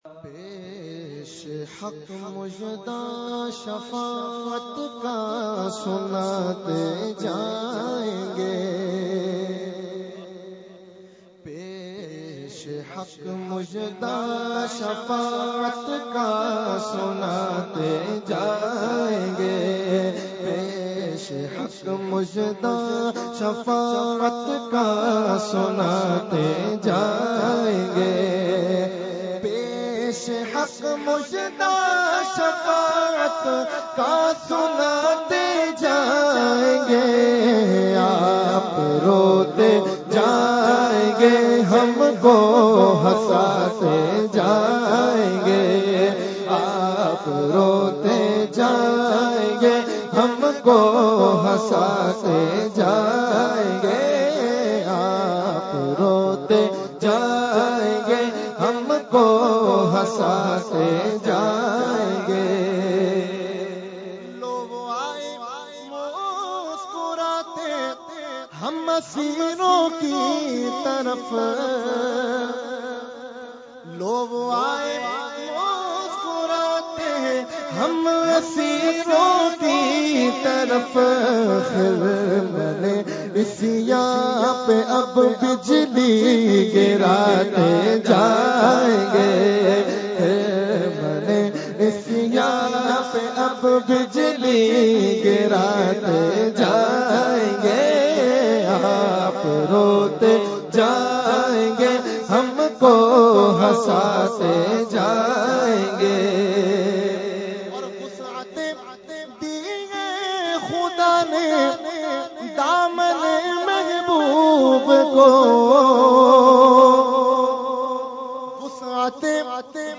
Category : Naat | Language : UrduEvent : Urs Ashraful Mashaikh 2018